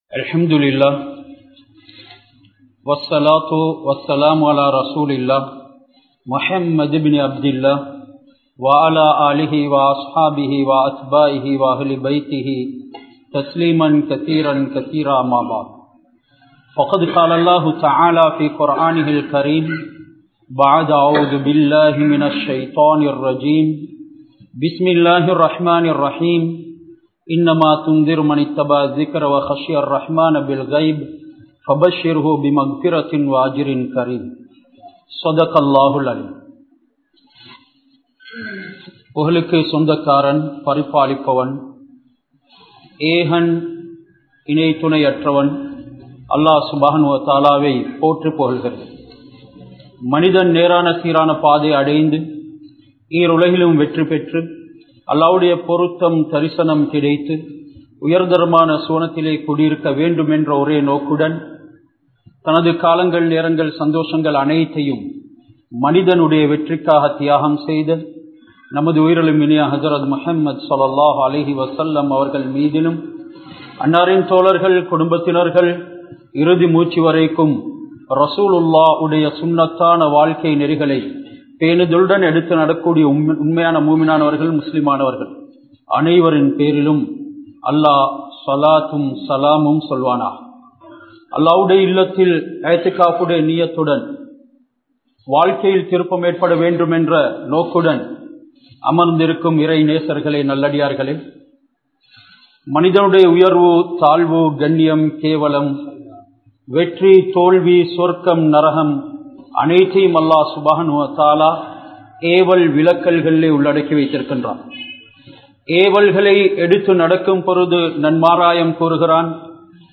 Oh Syria Muslimkale! (ஓ சிரியா முஸ்லிம்களே!) | Audio Bayans | All Ceylon Muslim Youth Community | Addalaichenai